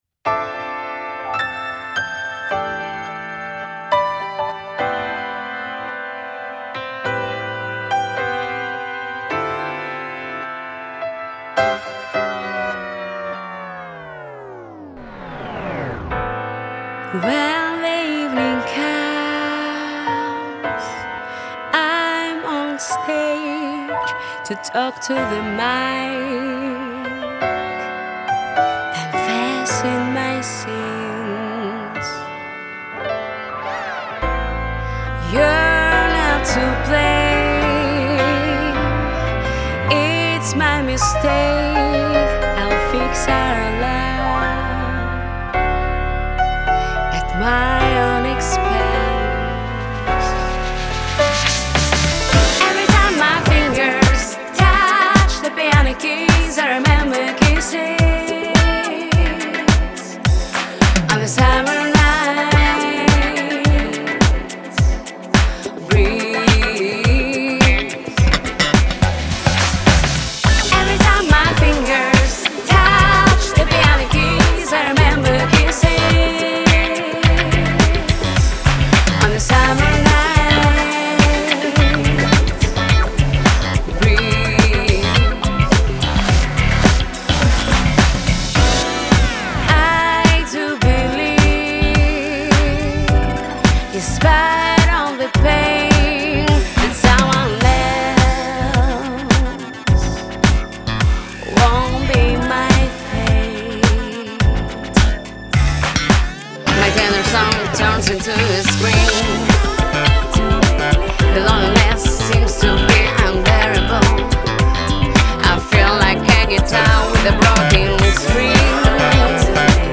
Everytime. Синти-поп.